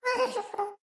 moan7.mp3